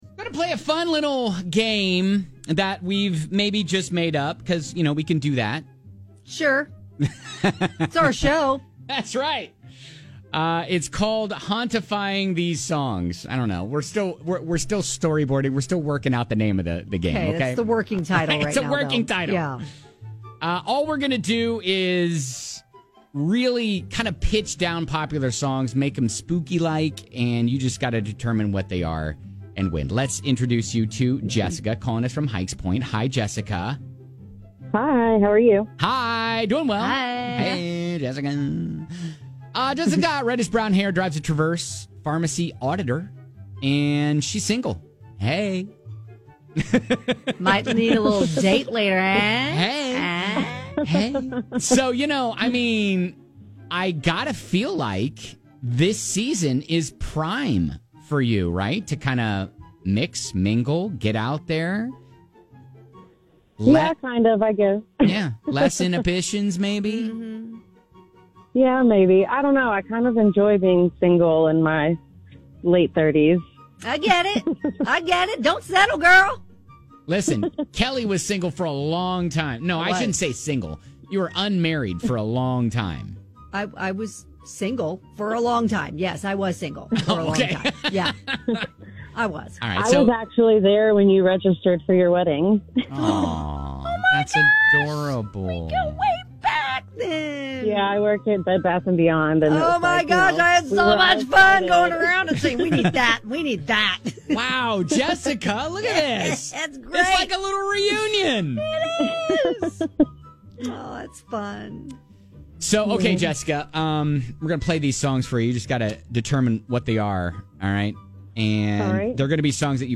We've pitched the songs down to make them sound creepy, you guess and win!